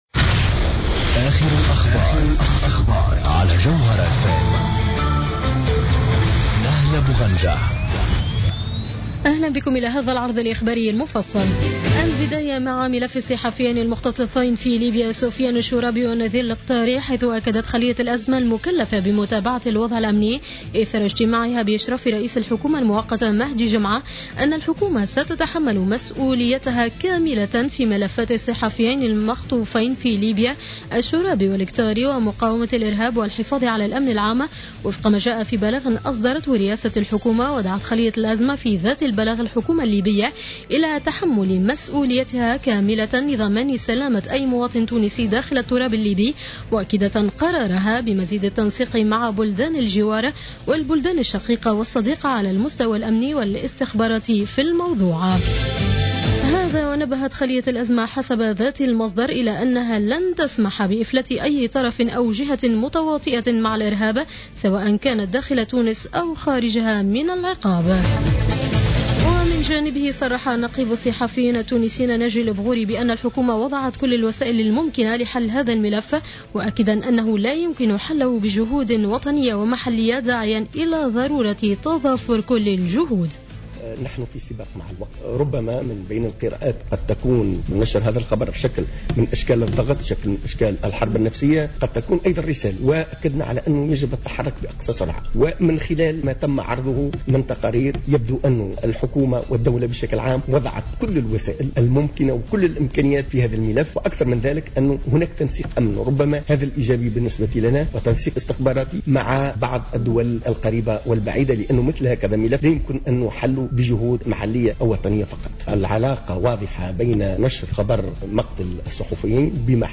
نشرة أخبار منتصف الليل ليوم الاحد 11-01-14